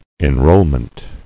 [en·roll·ment]